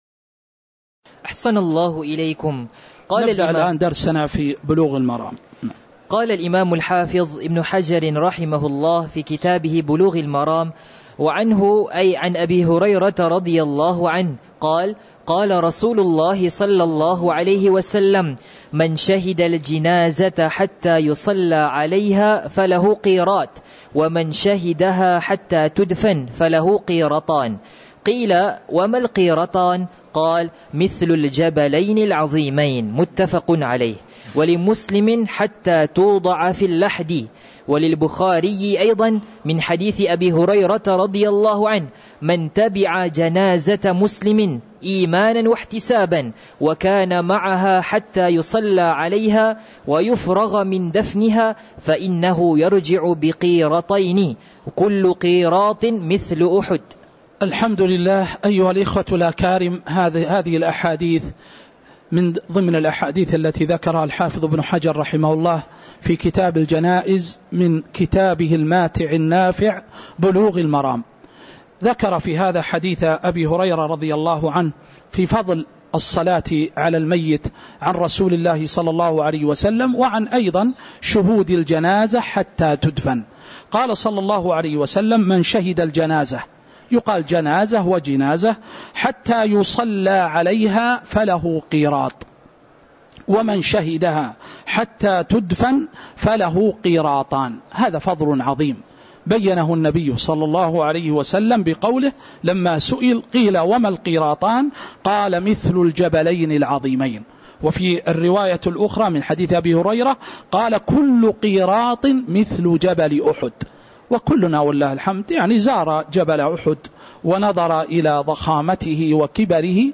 تاريخ النشر ١٨ شعبان ١٤٤٥ هـ المكان: المسجد النبوي الشيخ